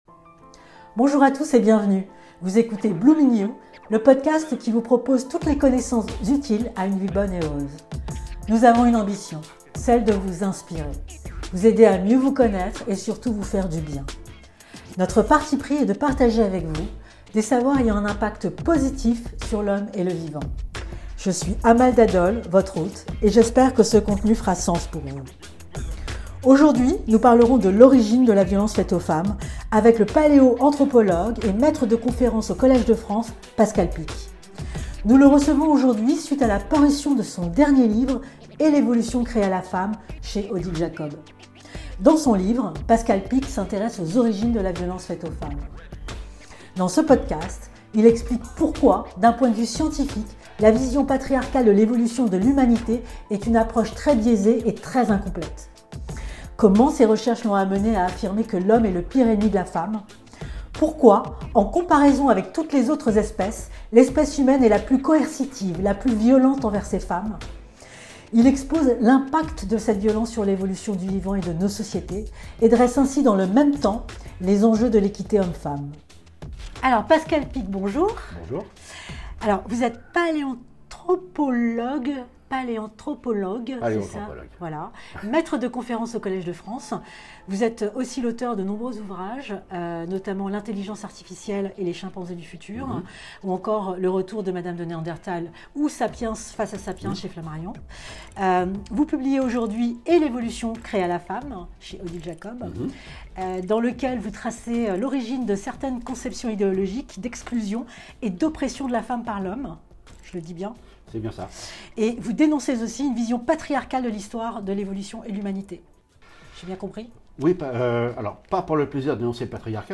Rencontre avec le paléoanthropologue Pascal Picq
La violence envers les femmes est-elle une fatalité évolutive ou une invention culturelle ? Dans cette interview, il explique comment s’est instaurée la domination masculine ?